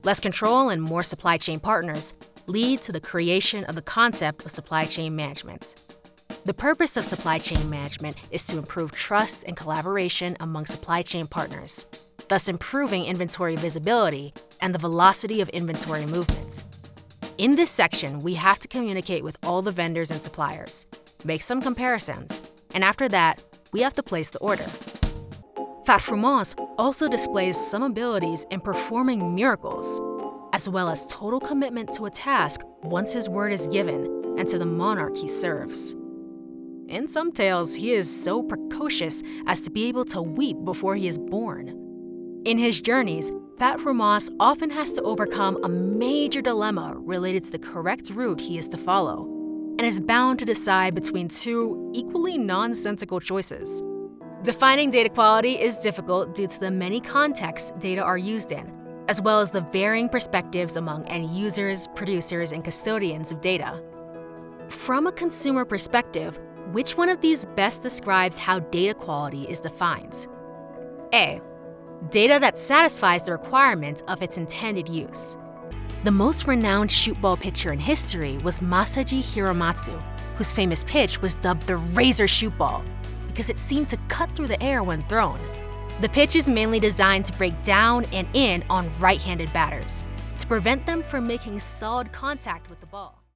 Video Game
Female
Approachable, Bubbly, Conversational, Cool, Streetwise, Young, Bright, Character, Children, Confident, Energetic, Engaging, Friendly, Natural, Smooth, Versatile, Warm
General American [native], New York [native], Caribbean (Jamaican/Grenadian), RP British, African (Zulu), American Southern (Alabama, etc.)
Microphone: Neumann TLM 103, Synco D2 shotgun microphone